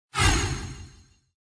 MagicianAttack.mp3